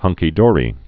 (hŭngkē-dôrē)